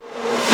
VEC3 Reverse FX
VEC3 FX Reverse 09.wav